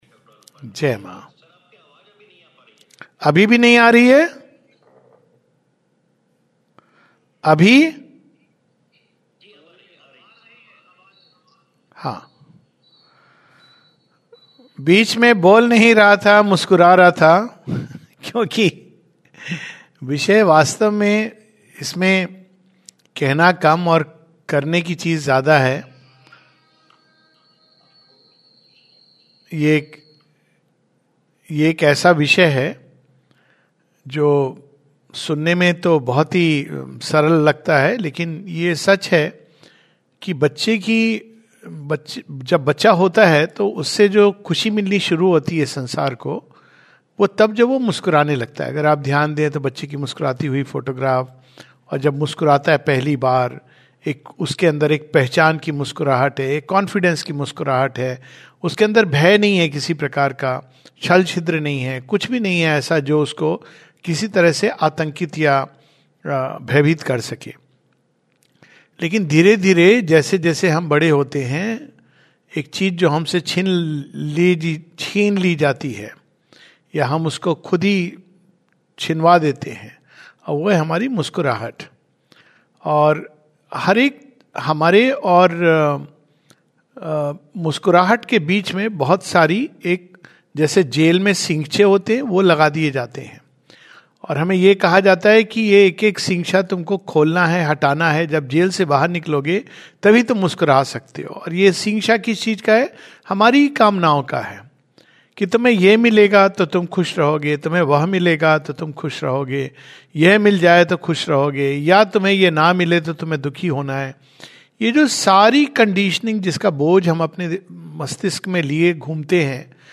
[Learn to Smile] This is a Webinar of the Hindi Zone based on the 2026 January issue of All India Magazine.